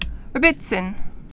(rĕbĭ-tsĭn)